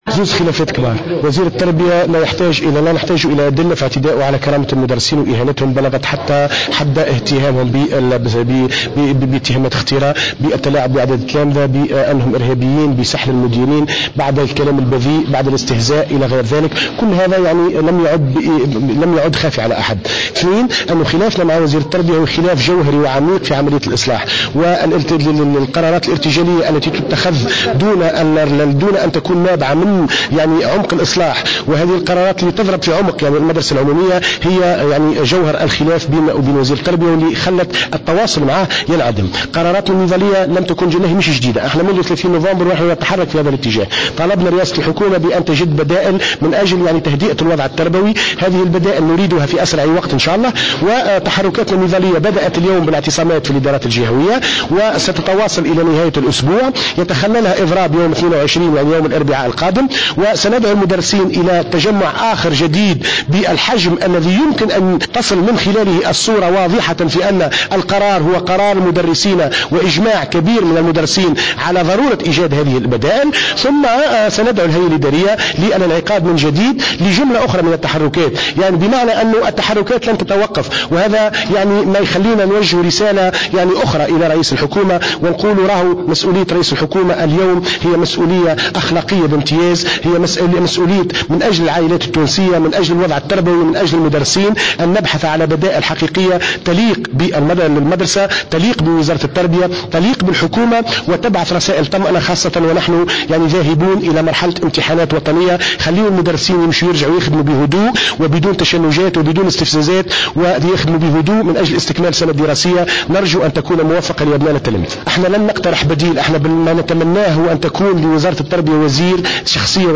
في تصريح لمراسلة الجوهرة اف ام خلال ندوة صحفية نظمتها النقابة للإعلان عن تحركاتها المبرمجة